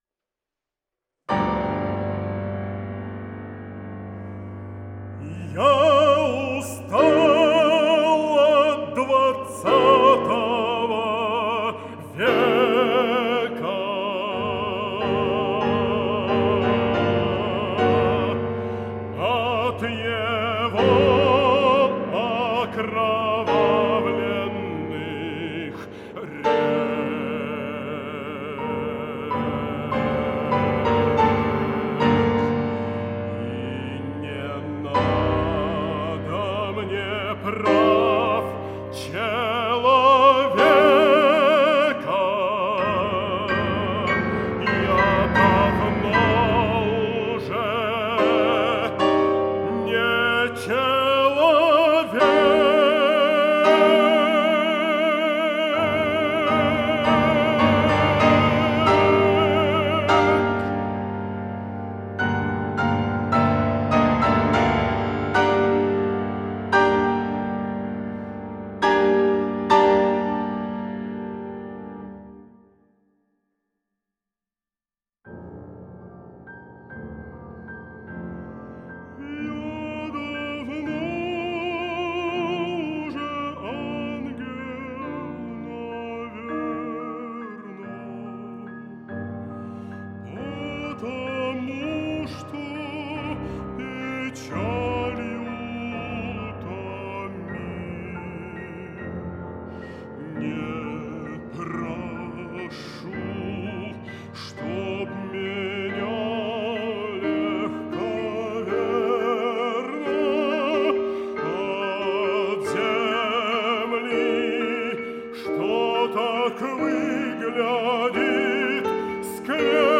вокальный цикл для баритона и фортепиано